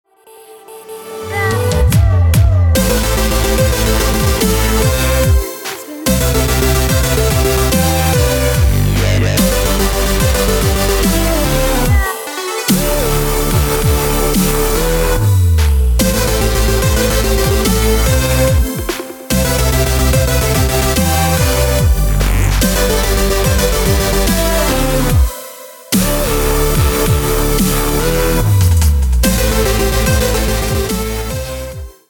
• Качество: 320, Stereo
Стиль: Dubstep, Chillstep